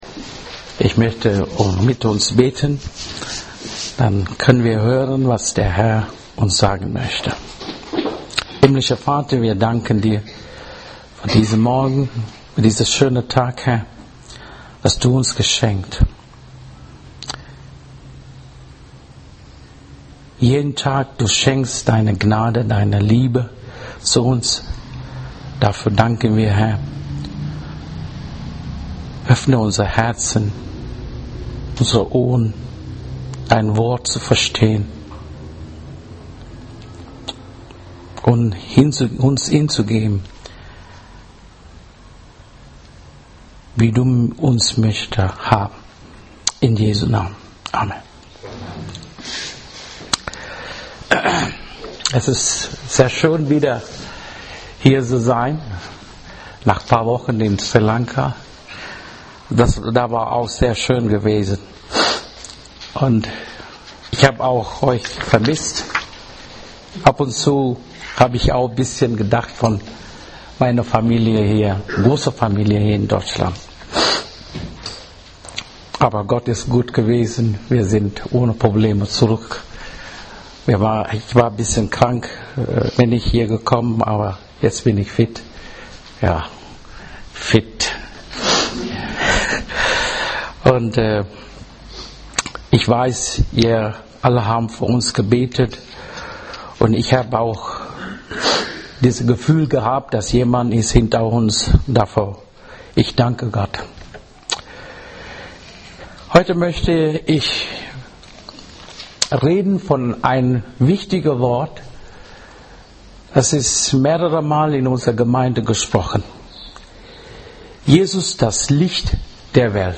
Evangelische Gemeinde Gevelsberg e.V. - Predigten